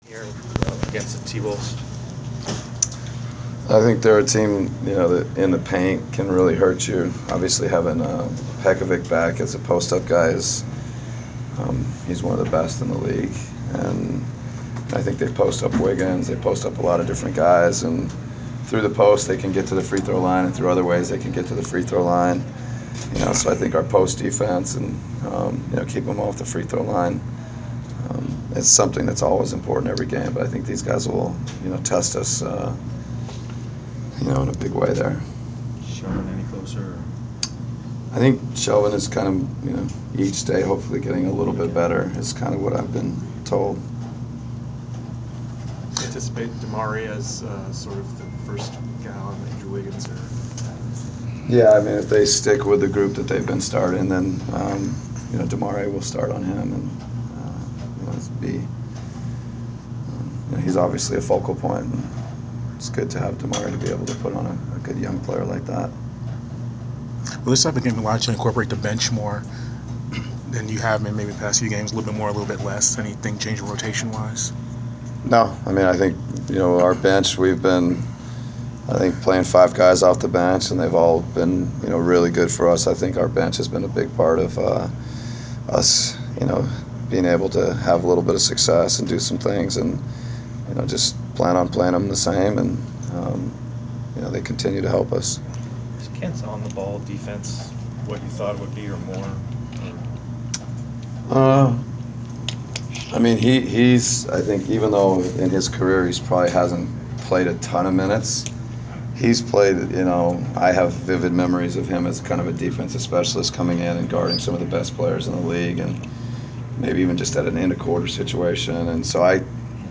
Inside the Inquirer: Pregame presser with Atlanta Hawks’ head coach Mike Budenholzer (1/25/15)
We attended the pregame presser (and even asked a question) of Atlanta Hawks’ head coach Mike Budenholzer before his team’s home contest against the Minnesota Timberwolves on Jan. 25. Topics included the keys to defeating Minnesota, the bench rotation and handing the extra attention of the Hawks’ franchise-record 15-game winning streak.